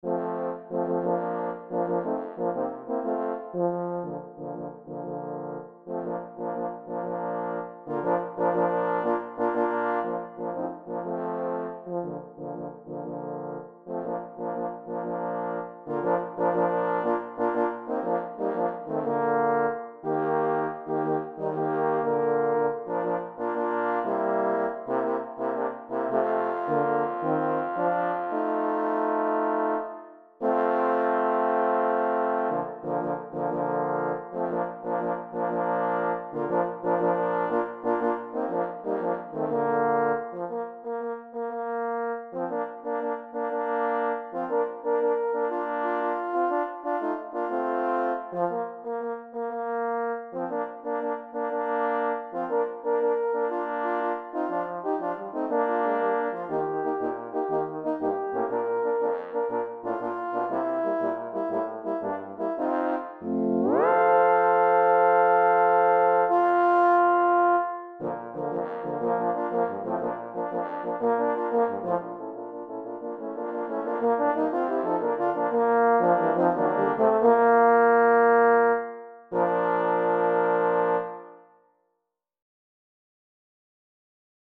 I have made a horn quartet out of this hymn.
Since most church musicians can't sing dotted 8th/16's, I changed this to 6/8, the way we sing it.